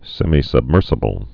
(sĕmē-səb-mûrsə-bəl, sĕmī-)